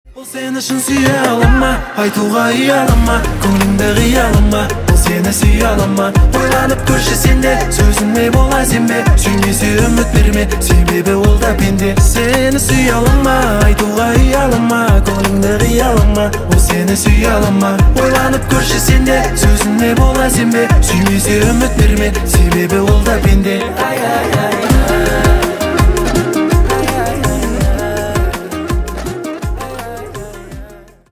восточные